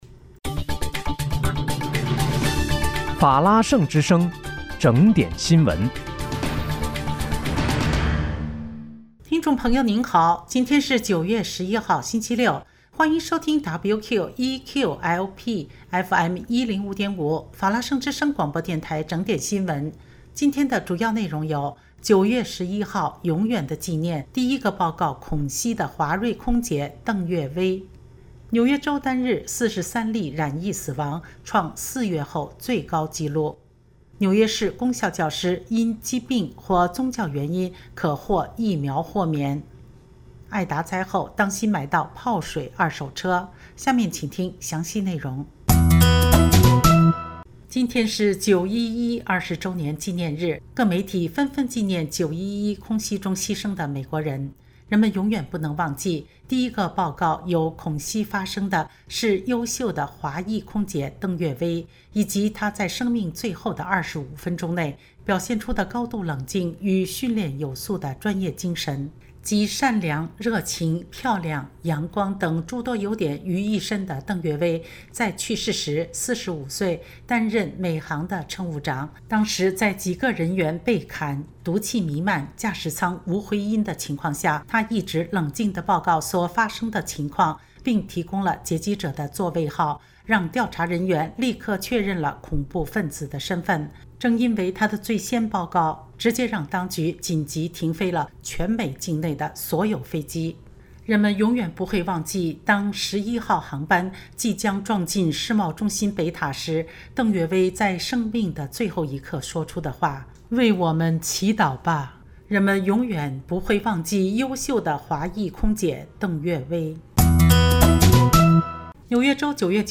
9月11日（星期六）纽约整点新闻